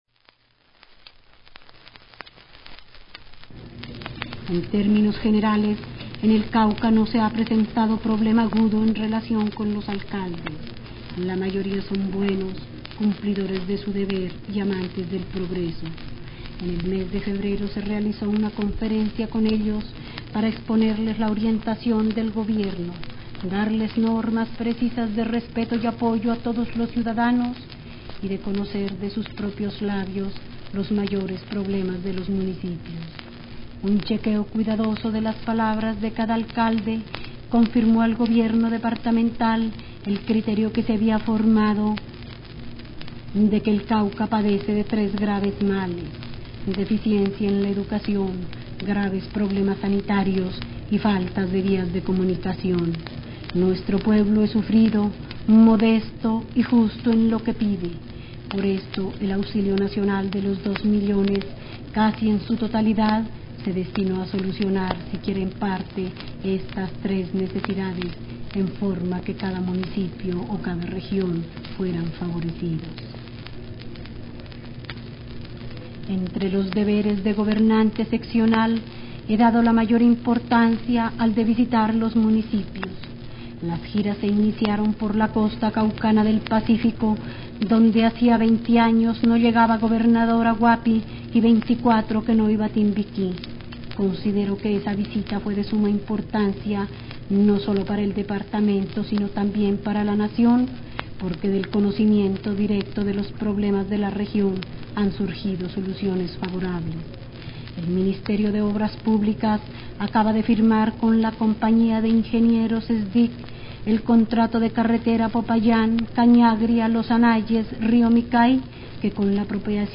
Presentamos una selección de frases pronunciadas por Josefina Valencia en un informe oral sobre su papel como Gobernadora del Cauca en 1956.